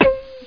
hammer.mp3